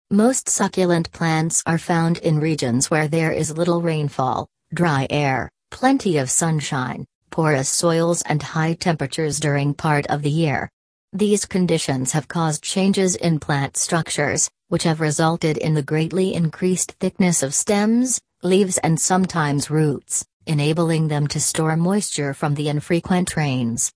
READ ALOUD
PTE Read Aloud Practice Sample